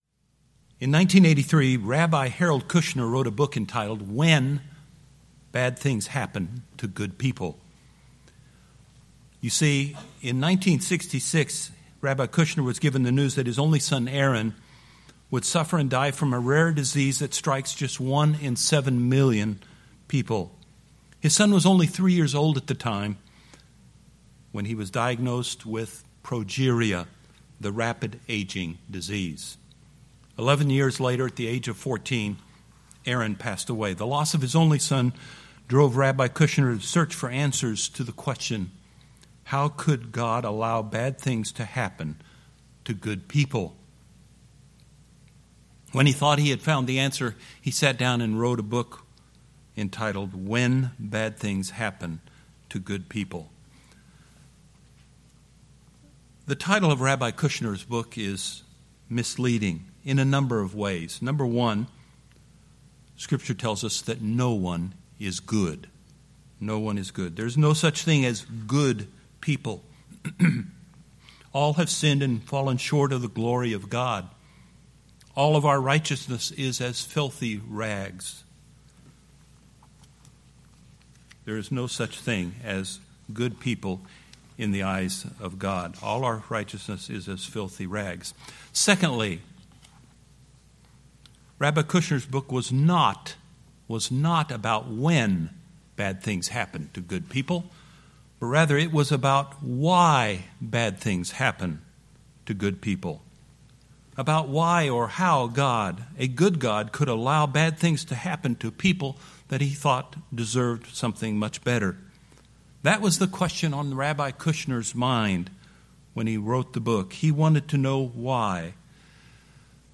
Habakkuk Sermon Series